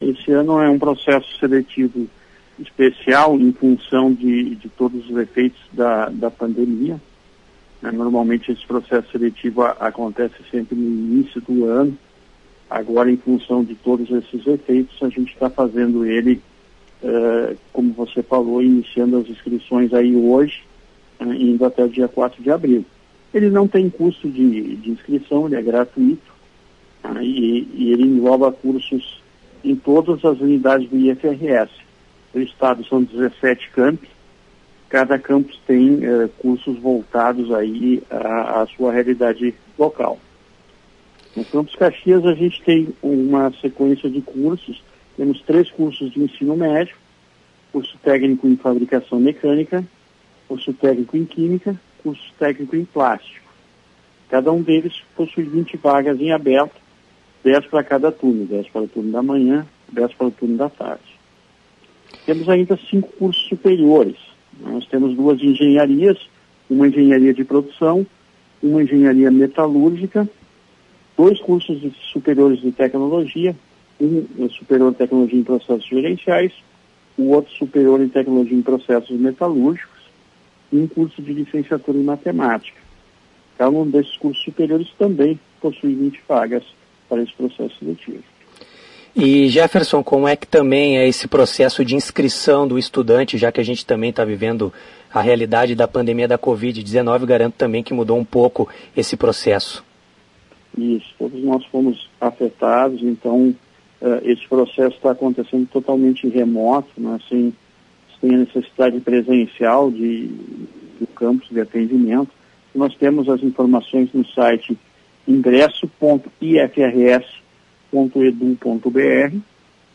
Durante entrevista à Tua Rádio São Francisco